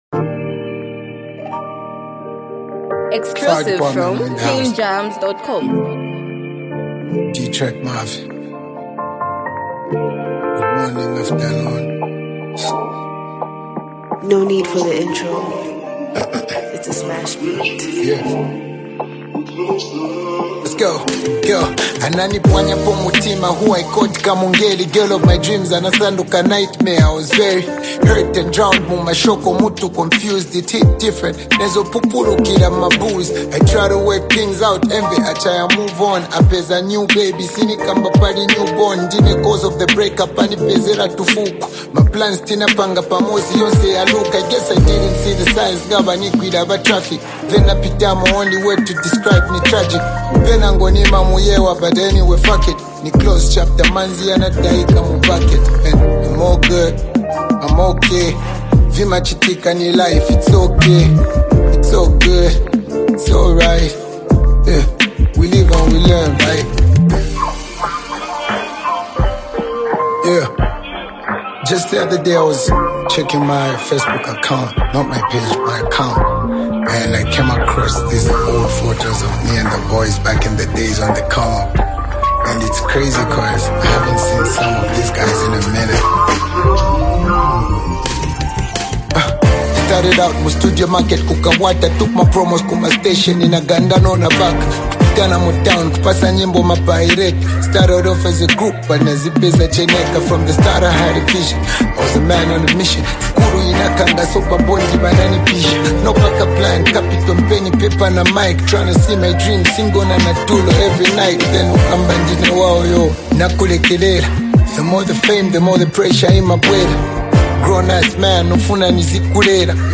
Zambian rap
a confident and uplifting track
Zambian hip-hop